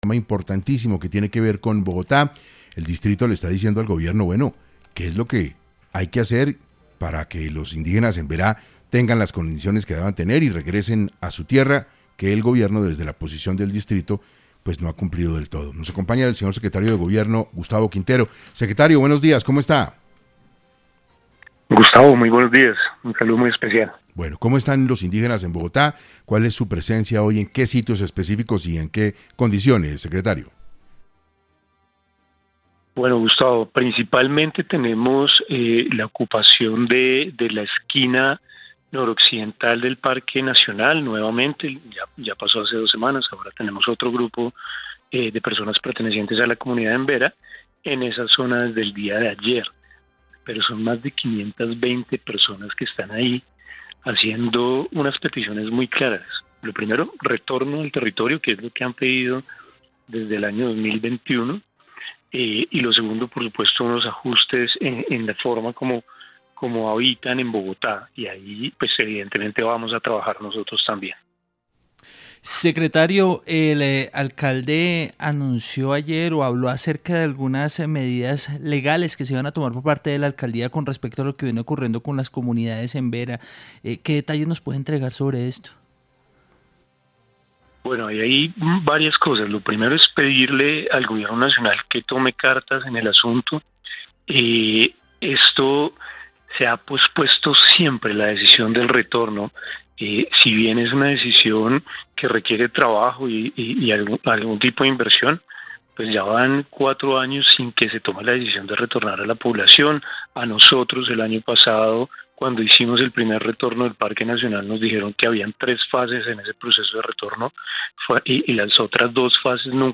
En entrevista para 6AM, El secretario de Gobierno de Bogotá, Gustavo Quintero, expuso la situación que atraviesa la ciudad e hizo un llamado de ayuda y atención al Gobierno Nacional.